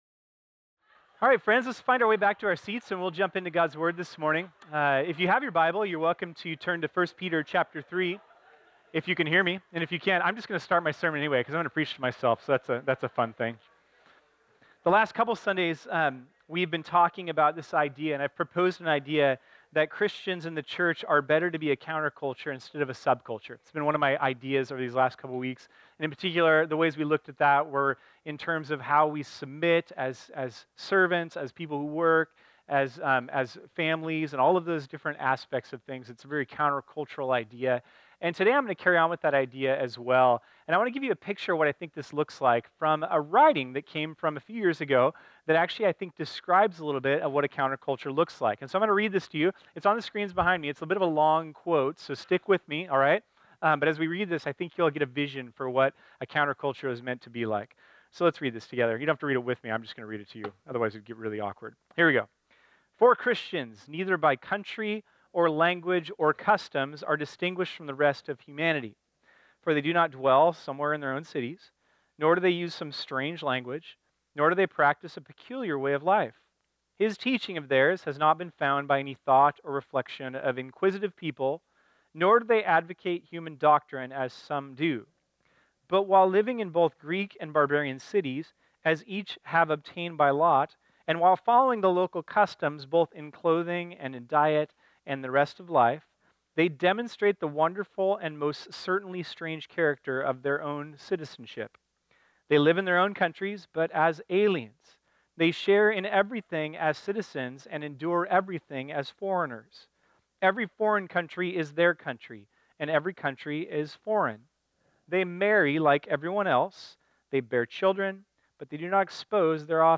This sermon was originally preached on Sunday, March 25, 2018.